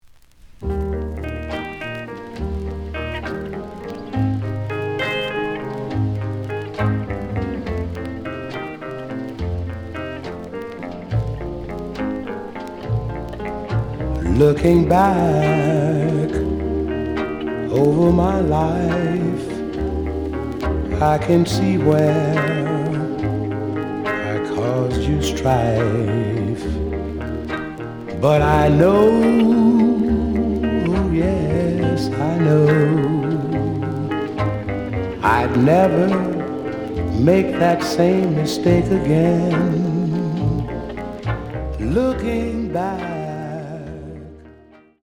試聴は実際のレコードから録音しています。
The audio sample is recorded from the actual item.
●Genre: Vocal Jazz